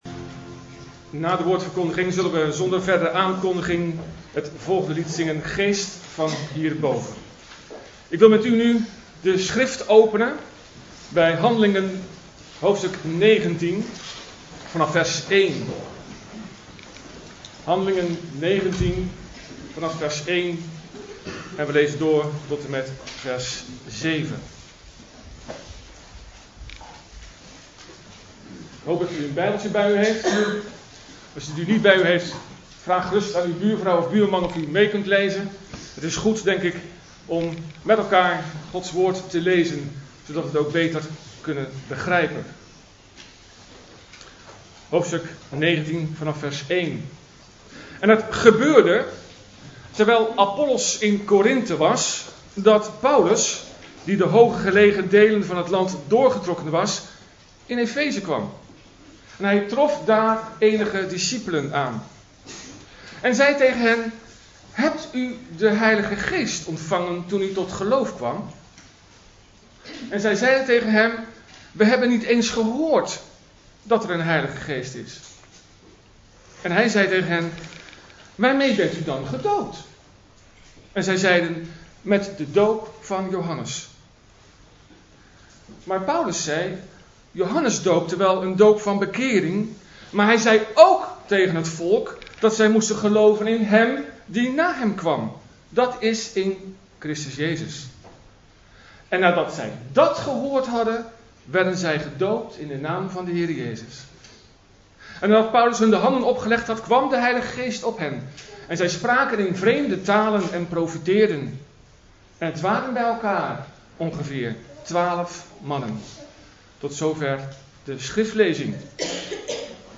Geen geloof zonder de Geest! (Doopdienst) – Reformatorische Baptistengemeente Heuvelrug